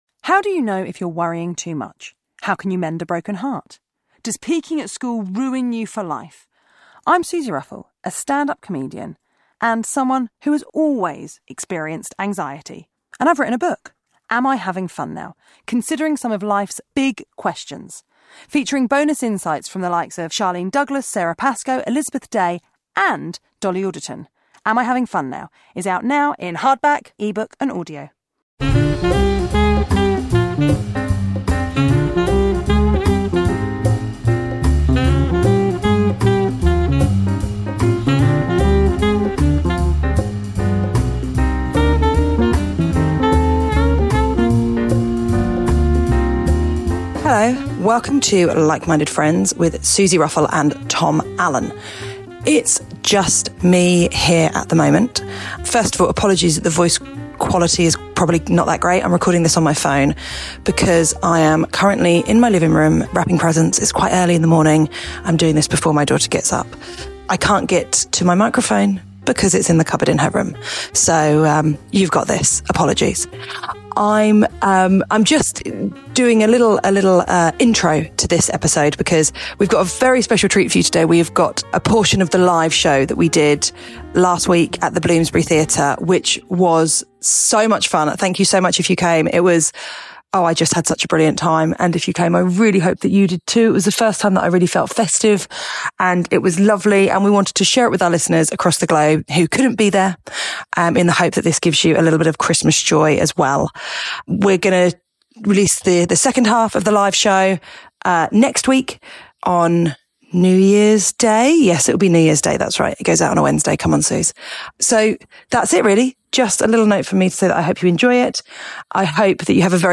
Like Minded Christmas Live - Part 1
Comedians and dearest pals Tom Allen and Suzi Ruffell chat friendship, love, life and culture....sometimes....